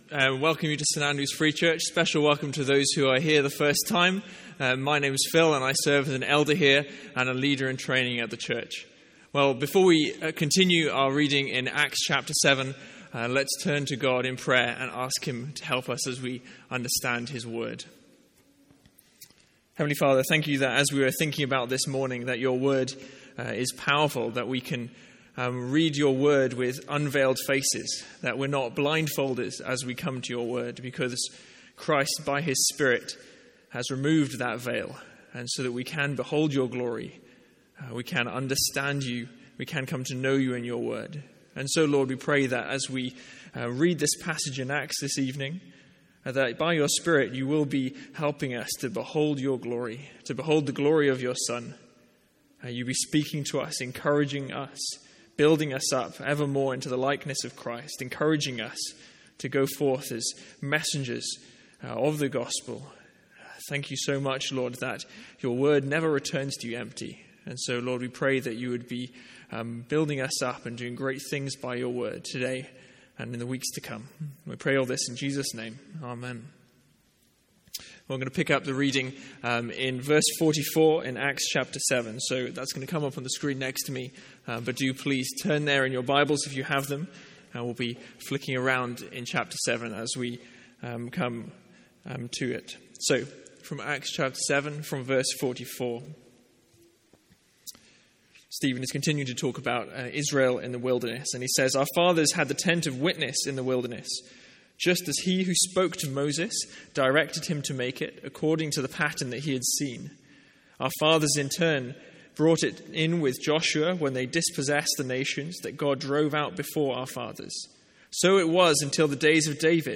Sermons | St Andrews Free Church
From our evening series in Acts.